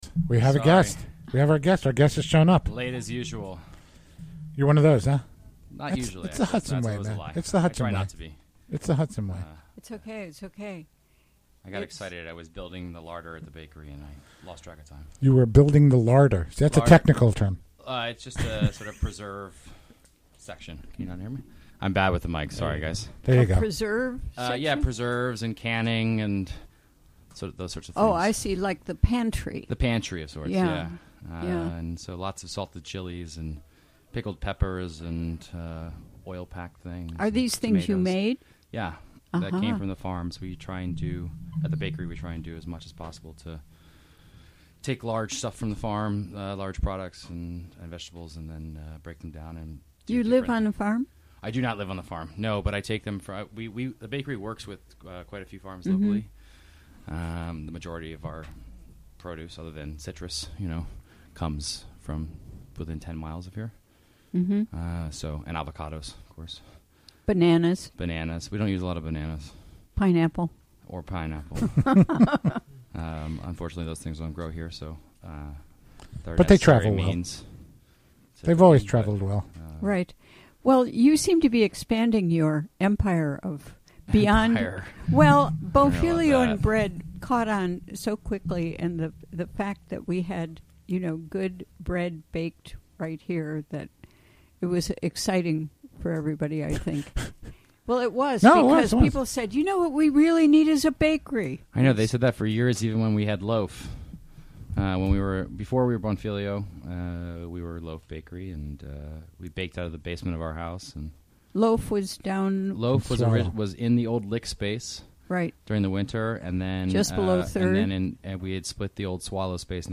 Recorded during the WGXC Afternoon Show Thursday, November 10, 2016.